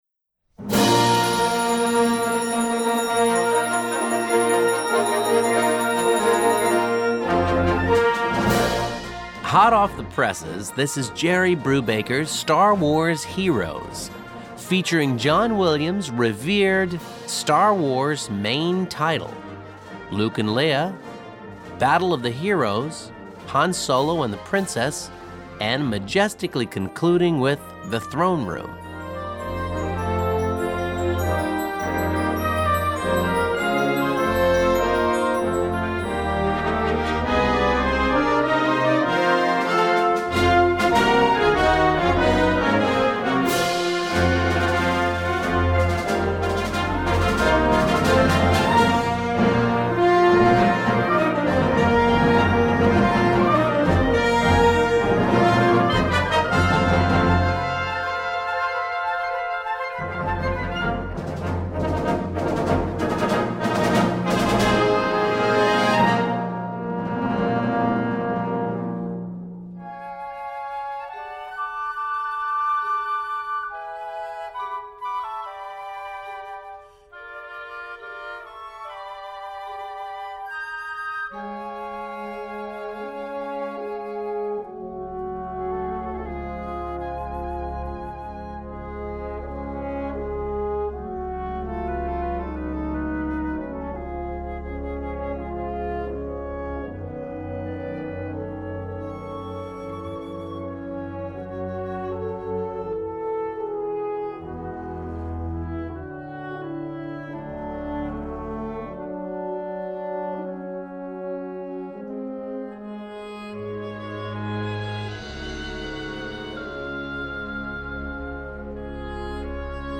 Gattung: Filmmusik-Medley
Besetzung: Blasorchester